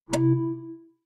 Звуки MacBook, iMac
Звук перемещения файла в Finder Mac